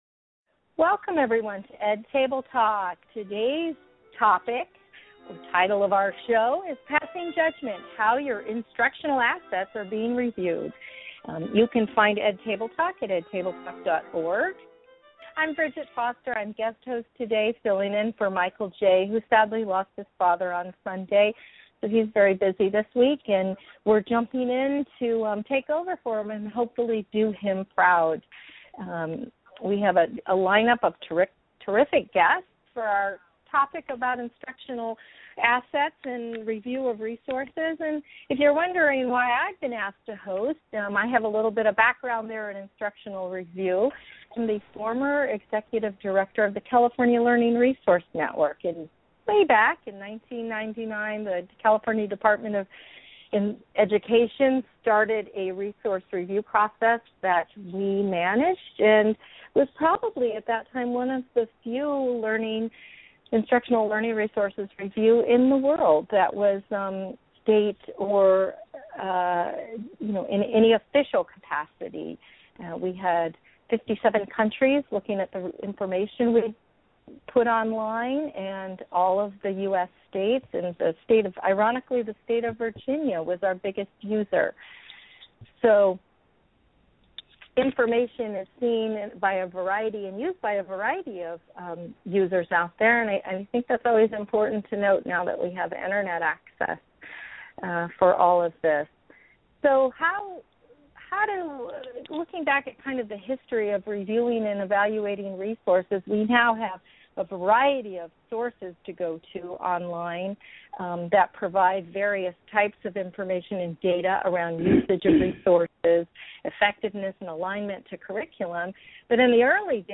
Airs live on December 15th 2015 @ 11amPT / 2pmET! While many educators use teaching materials they find and adapt there is still an important role for thoughtful evaluation and review of resources, particularly those that will receive the blessing of a governing agency.